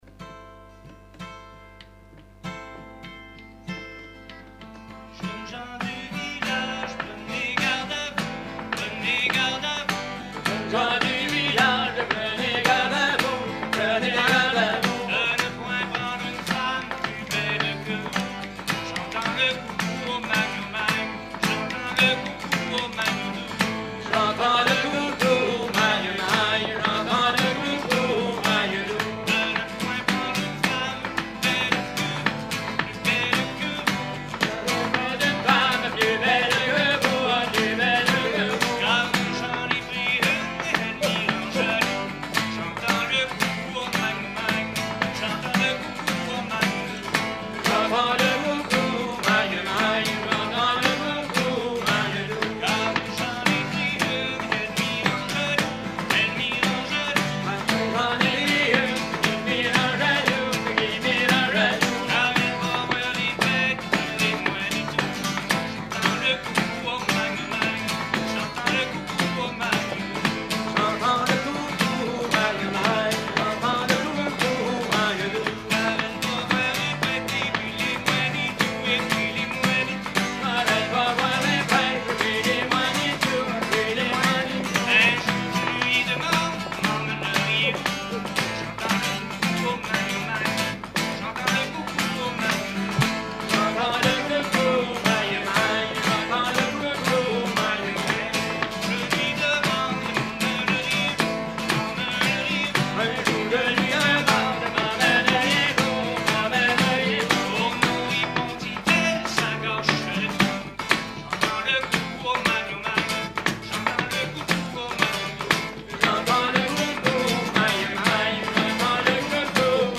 Genre laisse
Concert à la ferme du Vasais
Pièce musicale inédite